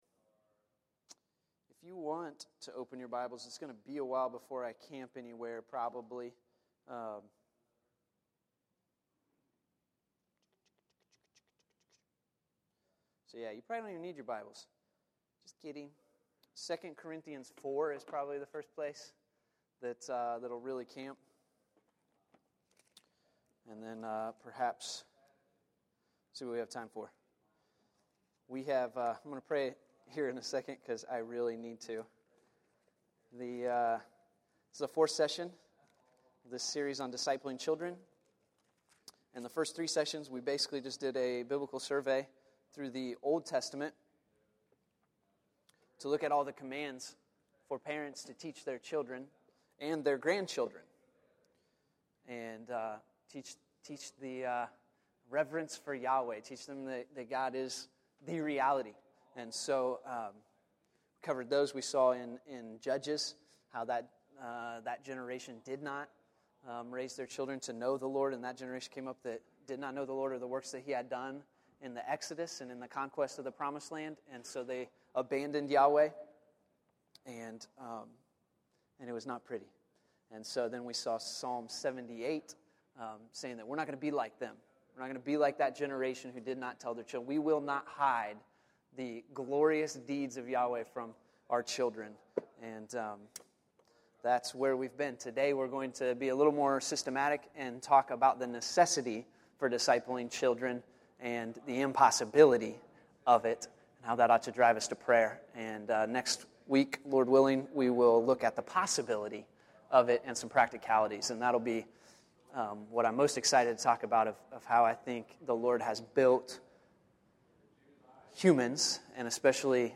The Necessity & Impossibility of Discipling Children (Session 4) May 19, 2013 Category: Sunday School | Back to the Resource Library